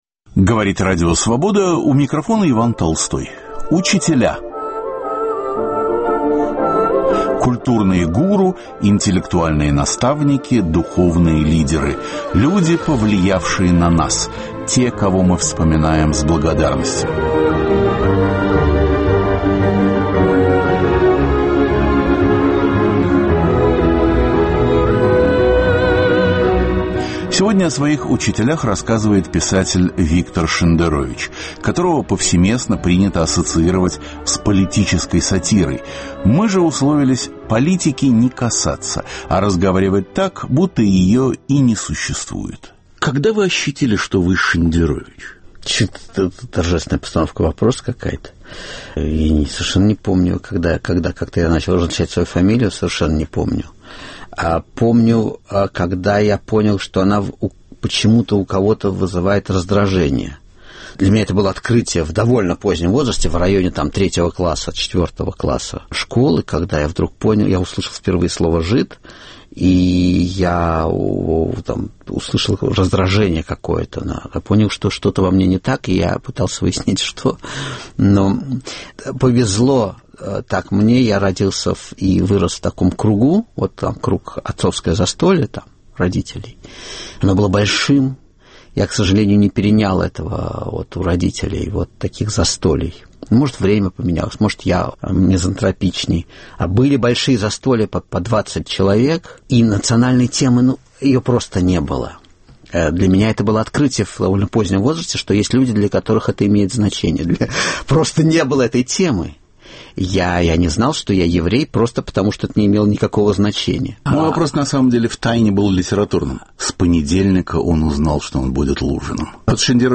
В серии "Учителя" разговор с писателем-сатириком, побывавшим в Праге. Никакой политики.